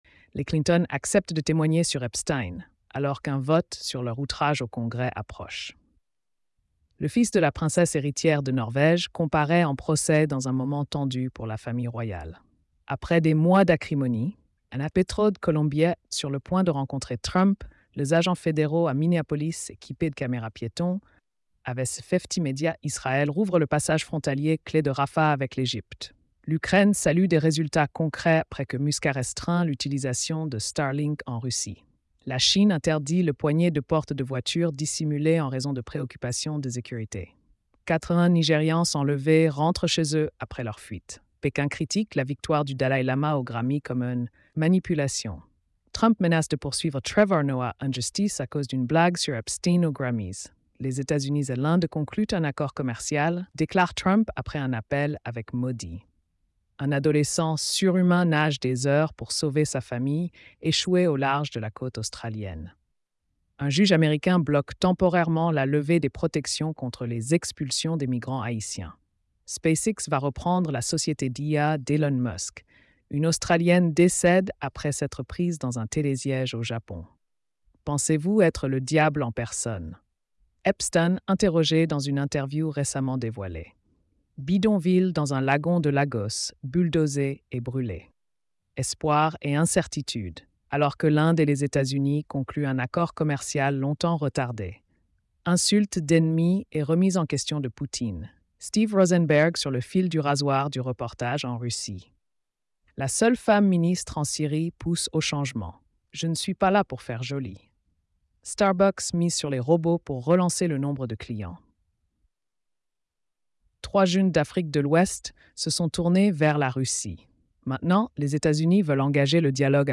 🎧 Résumé des nouvelles quotidiennes.